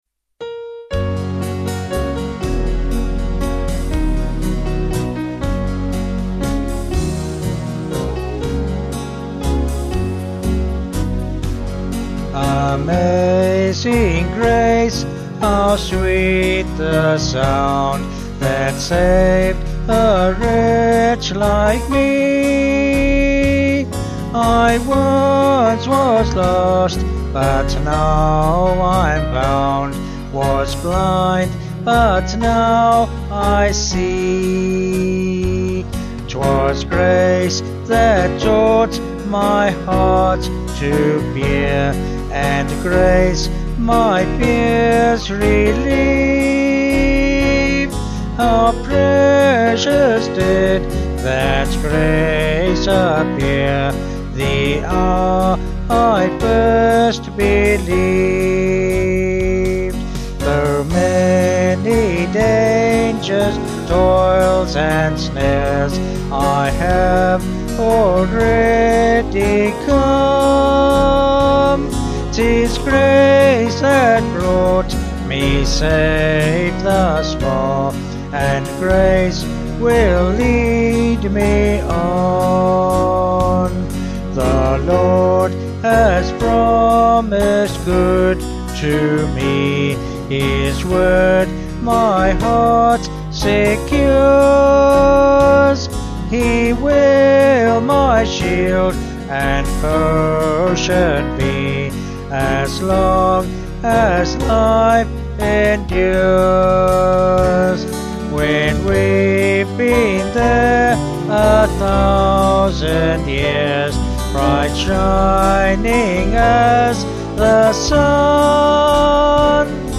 5/Gb-G
Vocals and Band   213.8kb Sung Lyrics